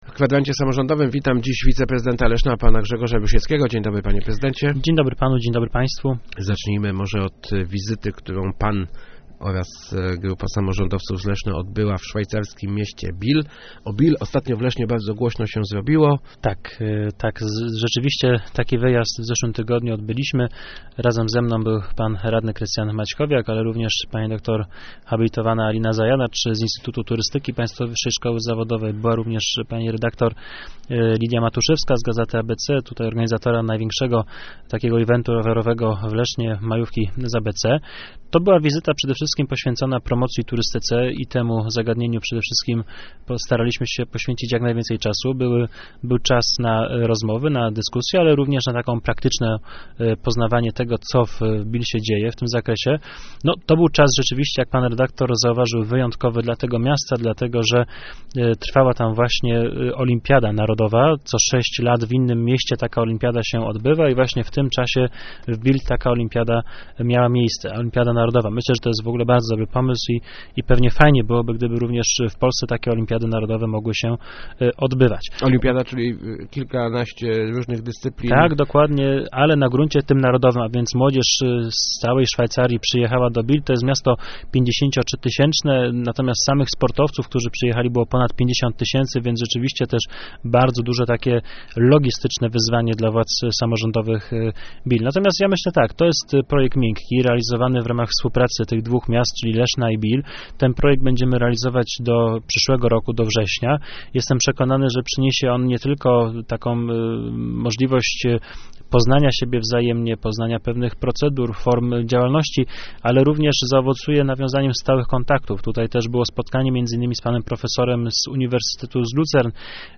Gościem Kwadransa był wiceprezydent Grzegorz Rusiecki.